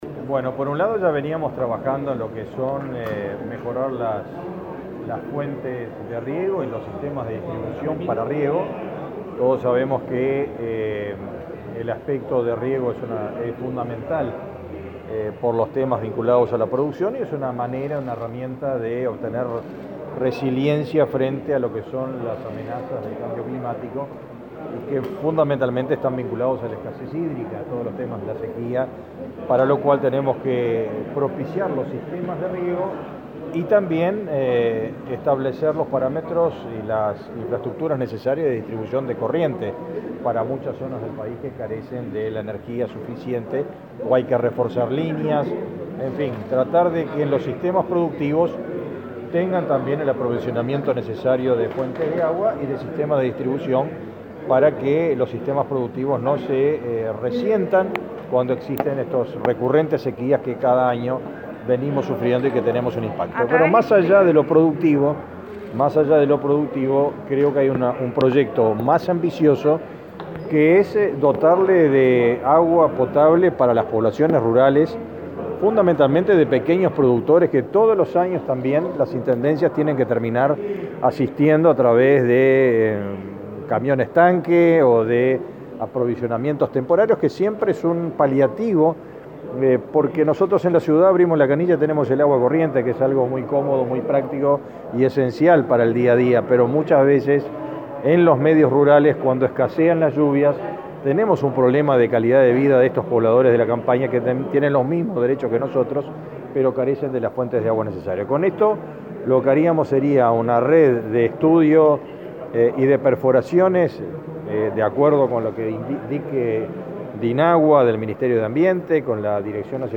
Declaraciones a la prensa del ministro de Ganadería, Fernando Mattos
El ministro de Ganadería, Fernando Mattos, dialogó con la prensa luego de participar de un almuerzo de trabajo de la Asociación de Dirigentes de